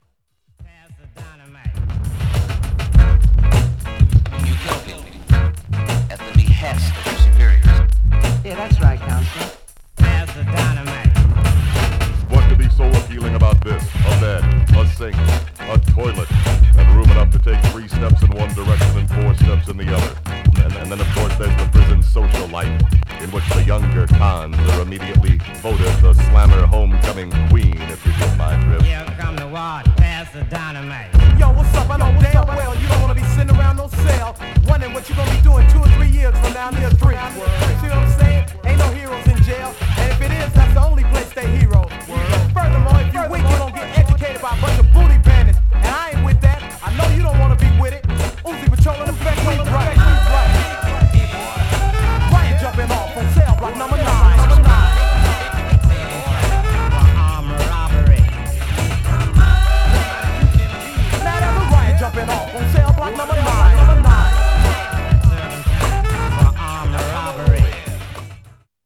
Styl: Hip Hop